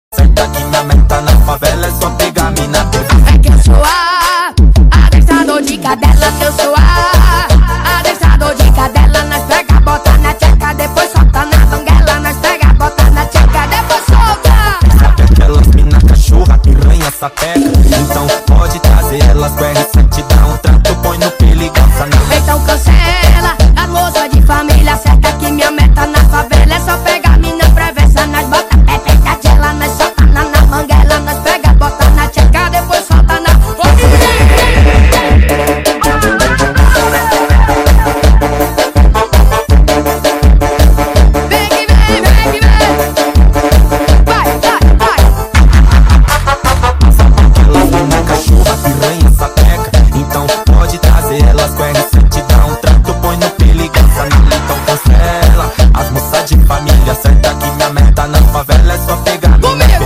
Curtiu a cabra cantando? compartiha sound effects free download